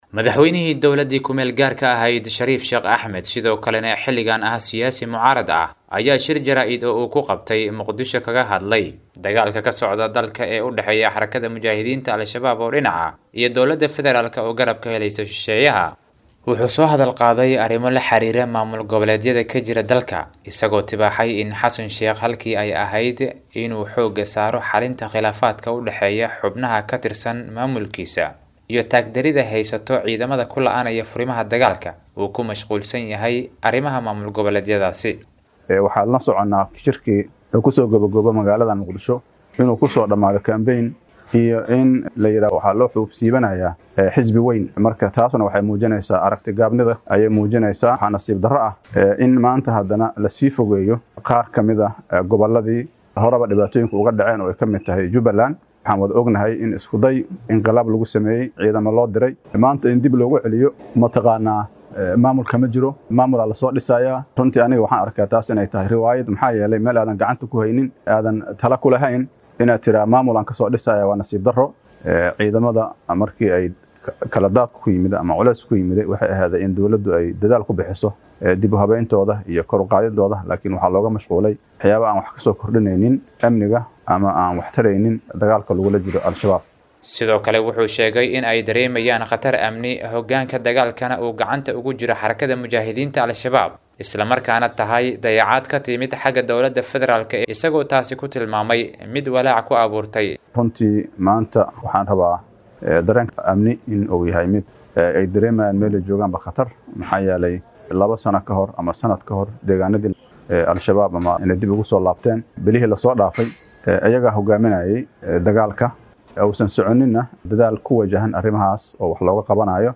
Madaxweynihii dowladdii KMG, Shariif Sheekh Axmad sido kalena xilligan ah siyaasi mucaarad ah, ayaa shir jaraa’id oo uu ku qabtay Muqdisho kaga hadlay, dagaalka ka socda dalka ee udhaxeeya Xarakada Mujaahidiinta Al-Shabaab dhinac ah iyo shisheeyaha iyo Dowladda Fedaraalka, wuxuuna sheegay in hogaanka dagaalka uu gacanta ugu jiro Xarakada Mujaahidiinta Al-Shabaab.